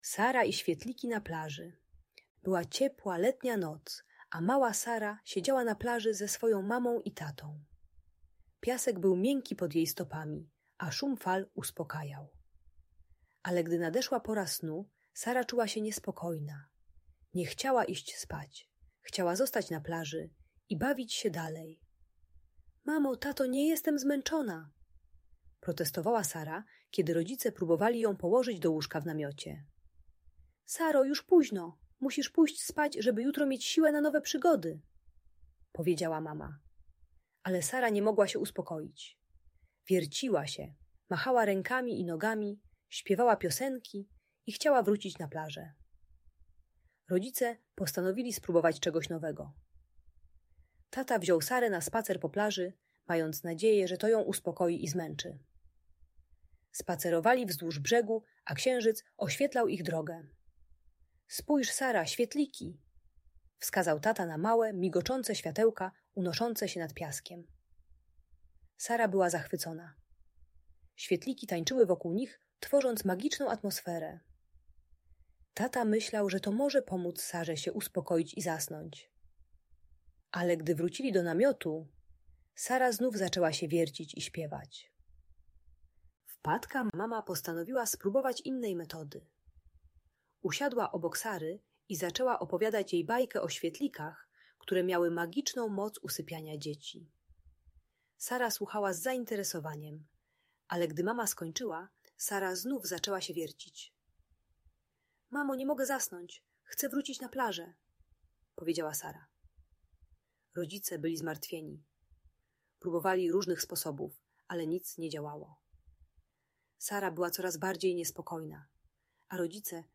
Sara i świetliki: magiczna historia na plaży - Audiobajka dla dzieci